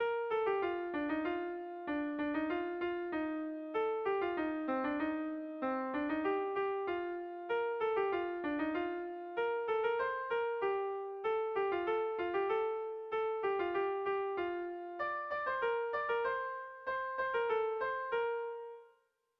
Irrizkoa
Lete, Xabier
Hamarreko txikia (hg) / Bost puntuko txikia (ip)
ABDEF